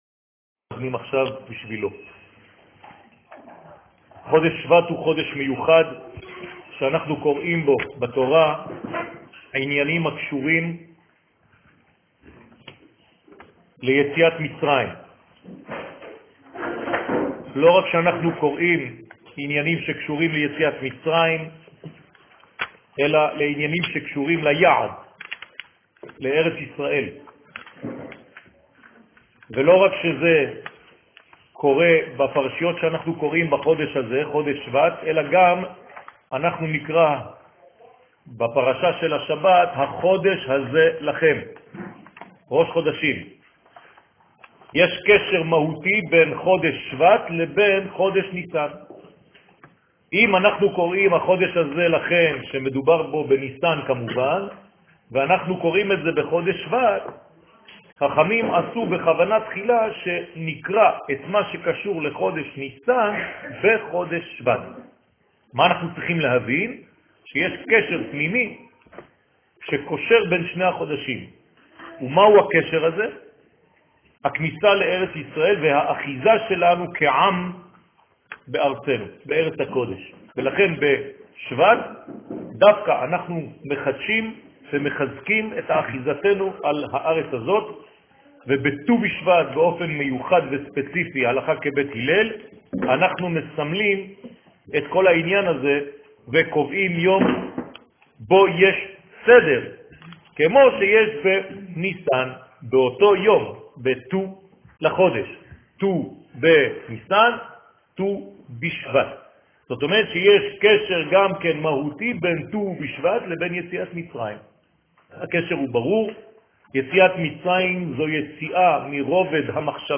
שיעור ראש חודש שבט תשע''ו